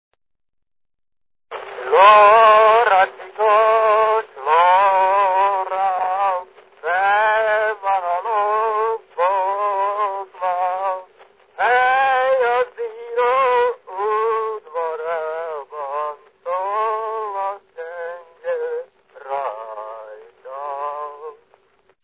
Dunántúl - Veszprém vm. - Dég
ének
Stílus: 8. Újszerű kisambitusú dallamok
Szótagszám: 6.6.8.6